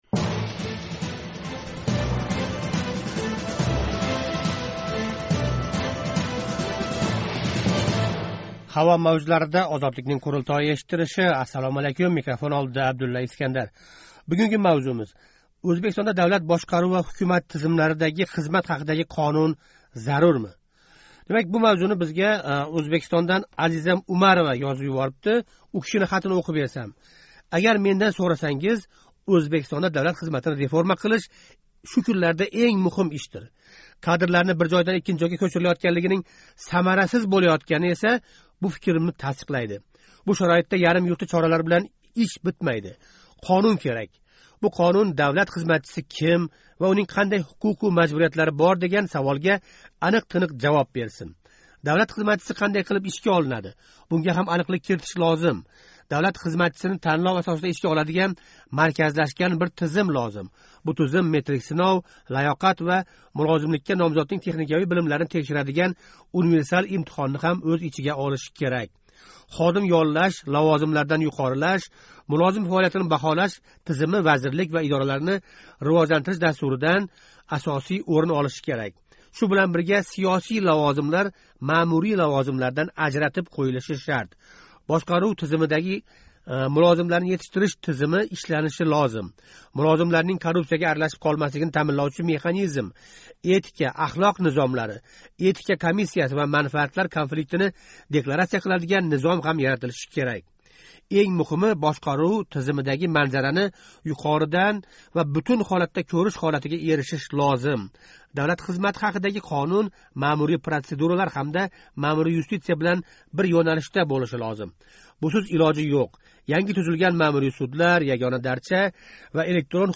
Қурултой эшиттириши